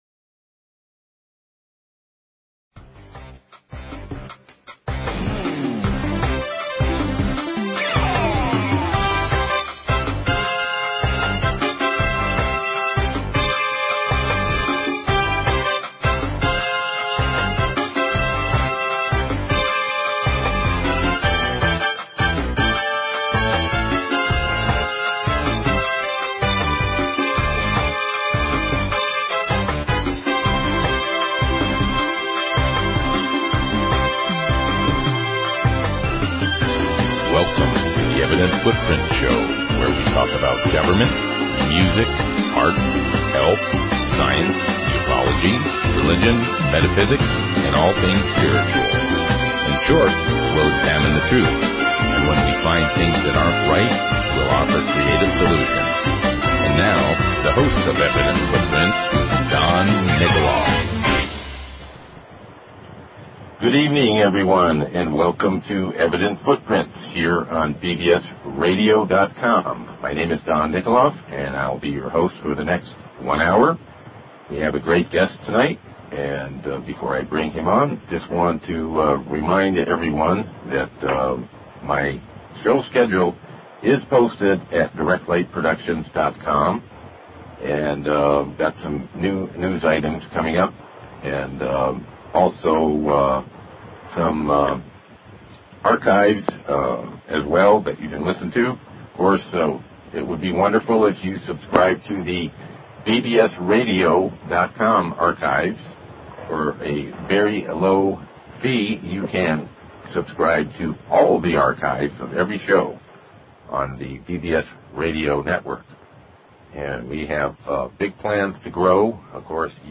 Talk Show Episode, Audio Podcast, Evident_Footprints and Courtesy of BBS Radio on , show guests , about , categorized as
Political activist and event organizer